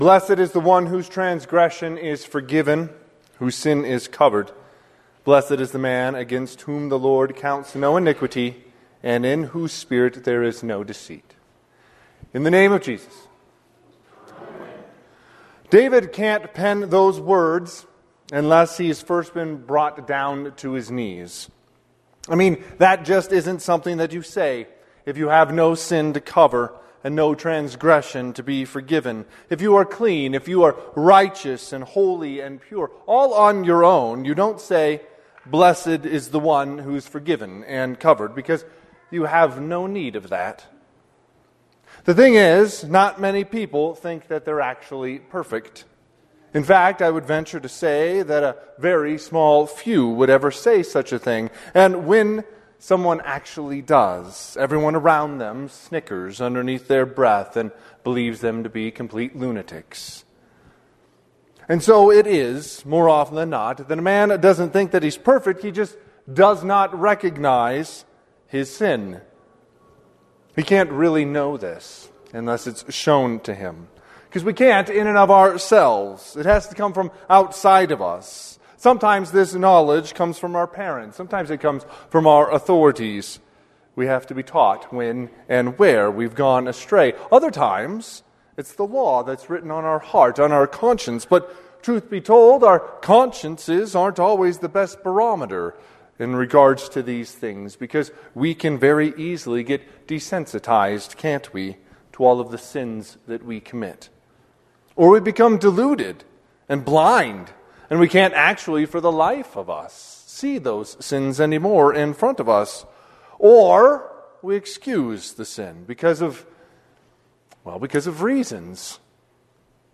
Sermon - 2/25/2026 - Wheat Ridge Evangelical Lutheran Church, Wheat Ridge, Colorado
Evening Prayer Service - Midweek Advent